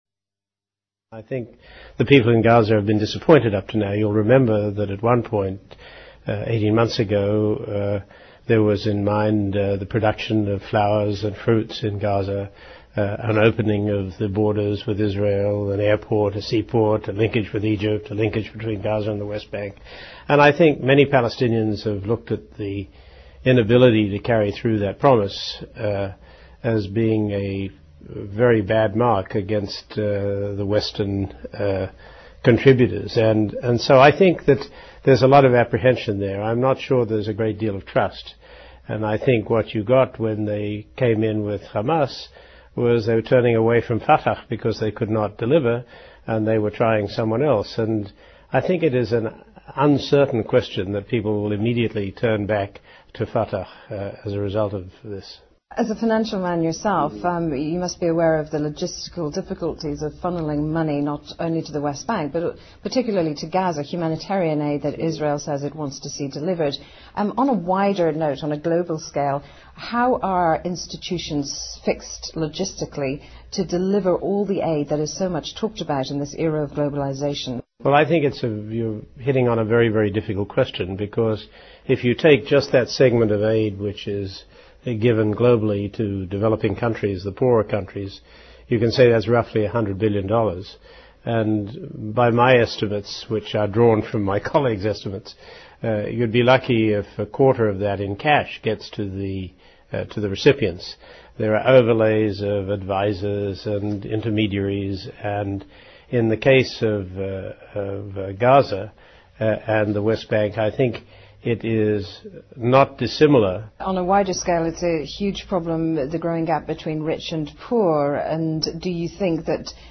访谈录 Interview 2007-06-26&06-28, 专访前世行行长 听力文件下载—在线英语听力室